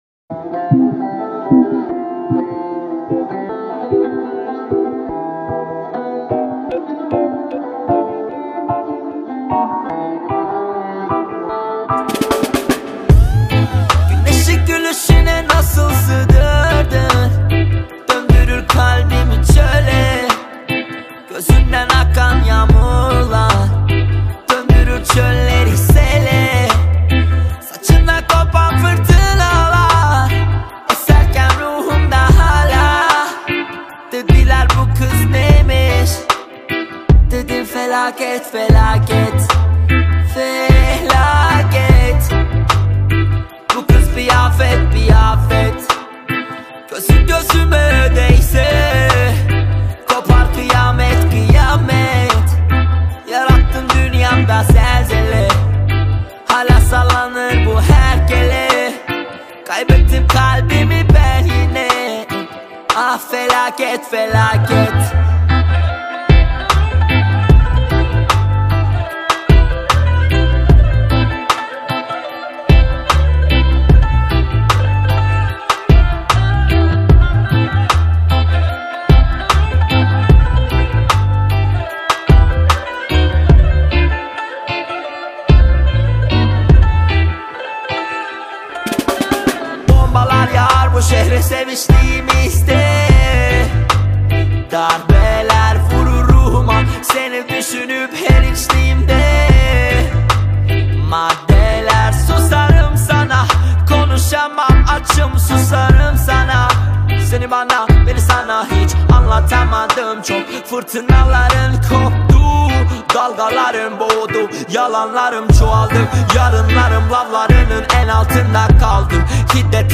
بخش دانلود آهنگ ترکی آرشیو